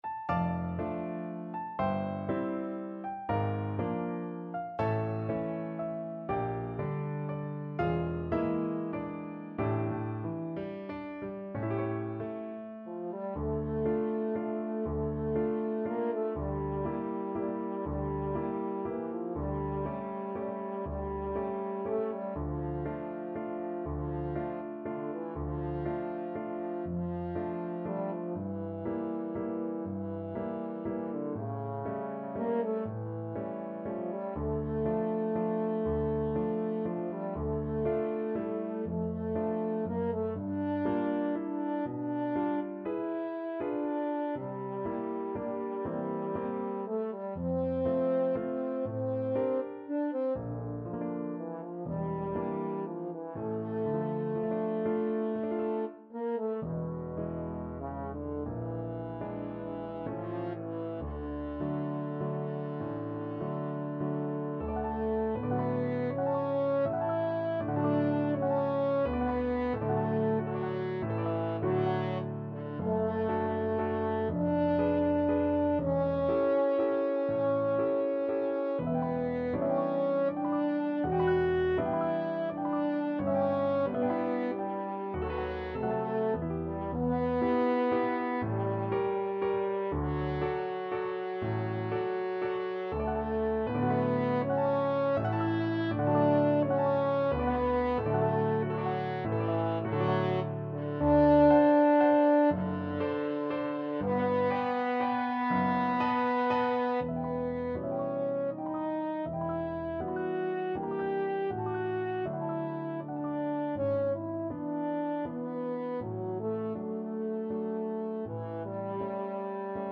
~ = 120 Lento
3/4 (View more 3/4 Music)
Classical (View more Classical French Horn Music)